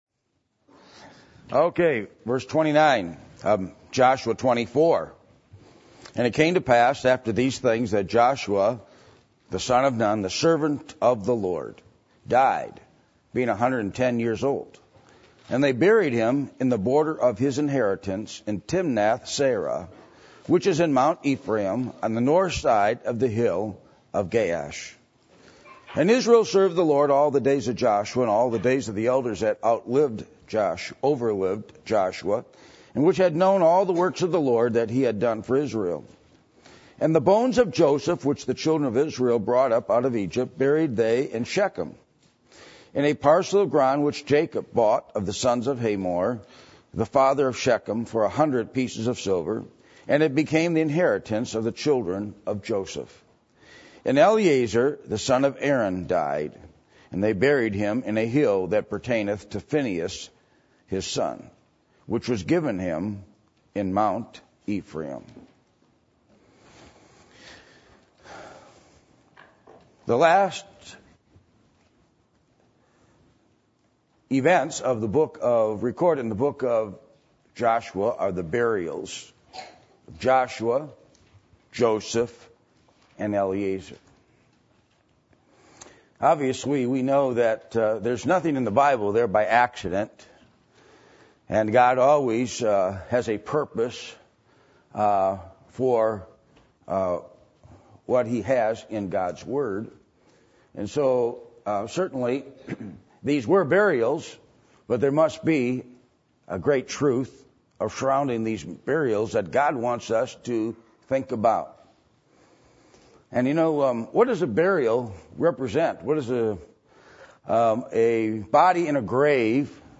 Joshua 24:29-33 Service Type: Sunday Evening %todo_render% « Are You Satisfied With God’s Work In Your Life?